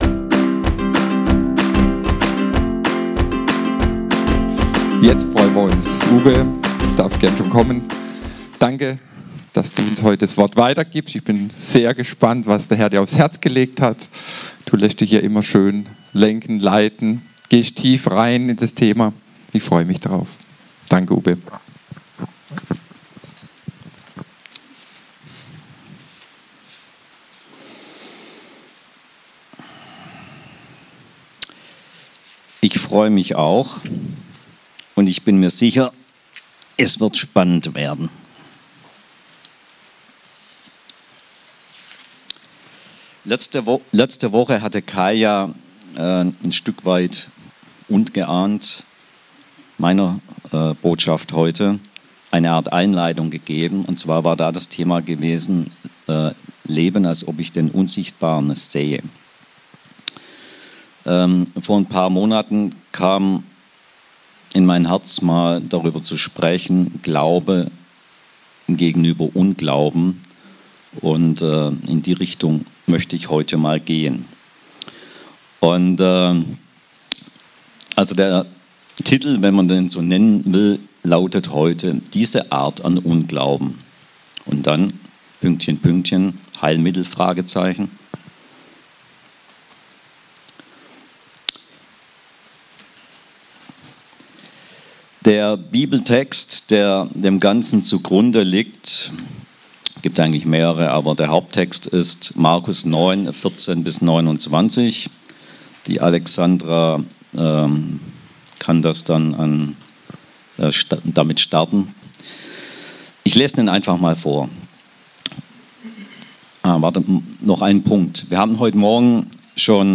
Die Bibel sagt jedoch etwas anderes darüber: Glauben wird von Unglauben ausgelöscht! Predigt